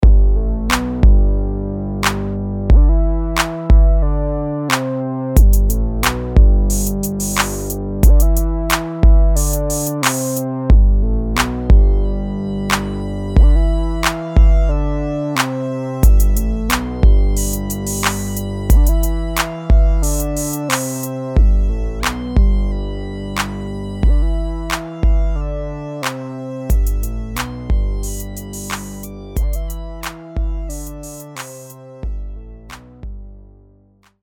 West Coast Rap Beats